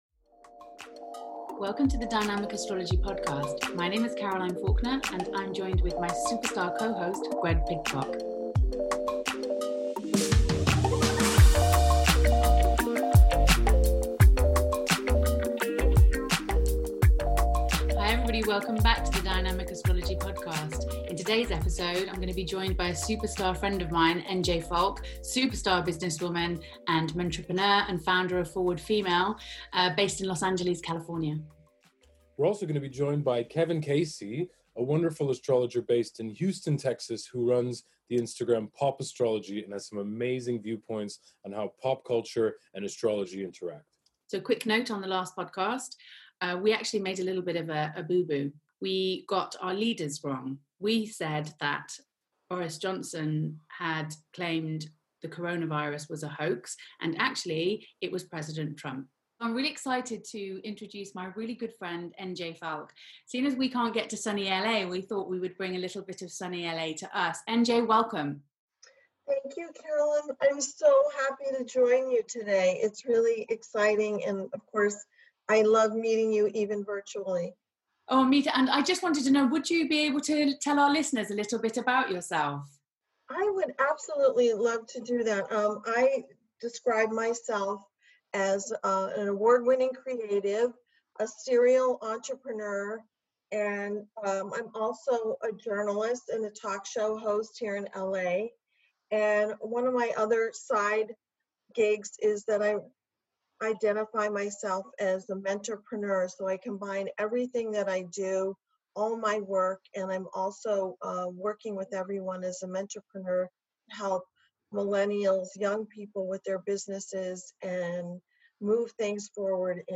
They join together to laugh a lot, share humour, tales and of course to discuss the situation in the USA during the COVID-19 pandemic. They discuss how they're surviving in lockdown, spiritual, and practical methods for your mind, body and soul.